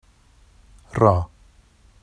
rɑ_184.mp3